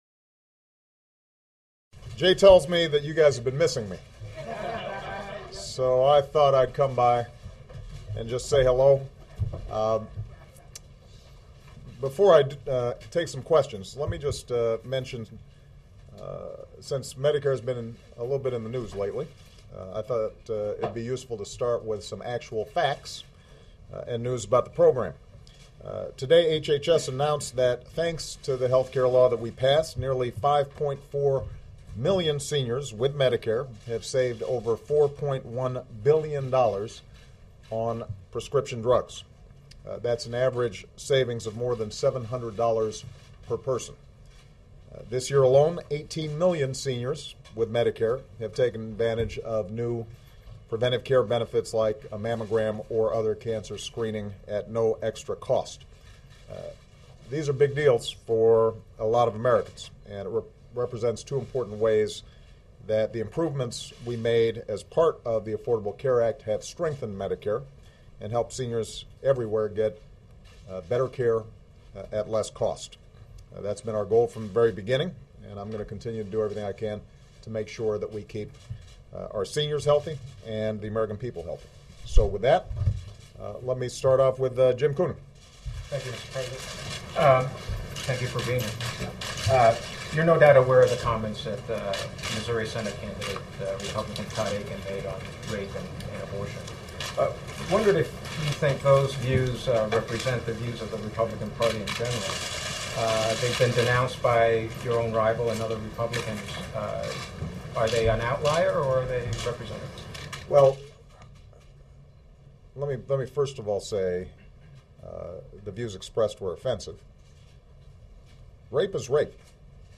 U.S. President Barack Obama speaks to reporters and answers questions at the White House daily briefing